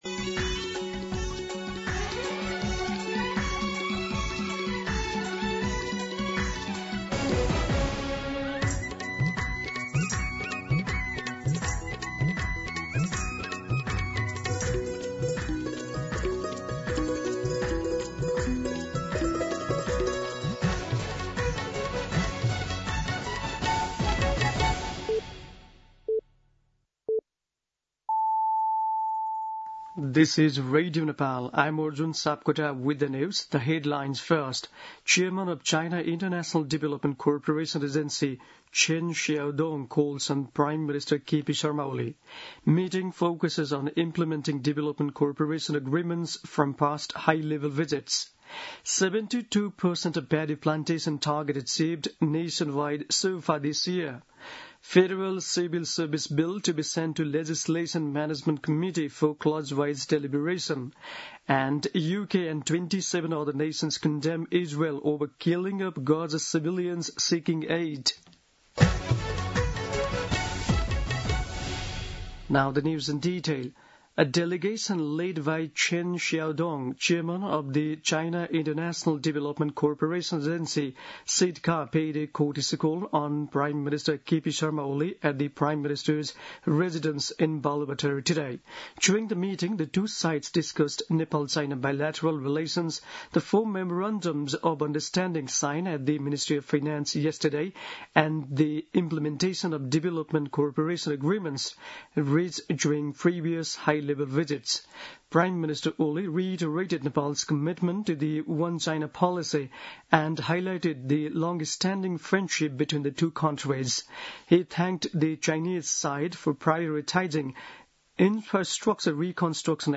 दिउँसो २ बजेको अङ्ग्रेजी समाचार : ६ साउन , २०८२
2pm-English-News-06.mp3